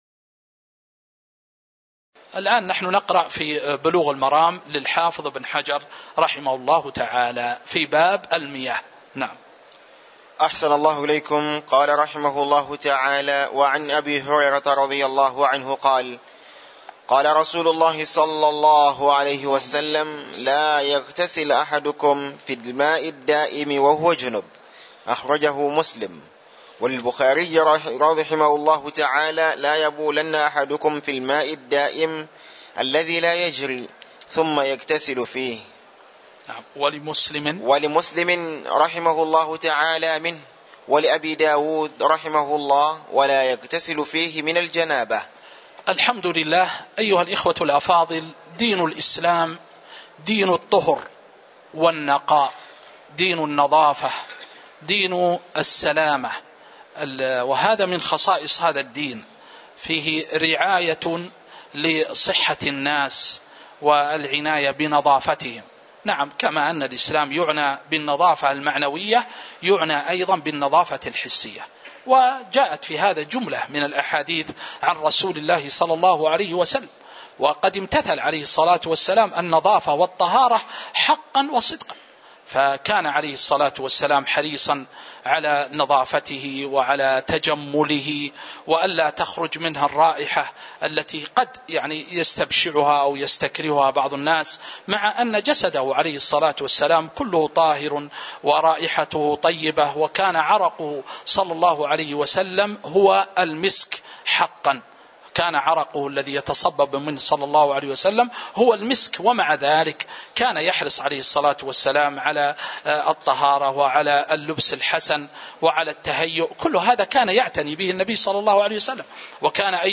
تاريخ النشر ٩ شوال ١٤٤٤ هـ المكان: المسجد النبوي الشيخ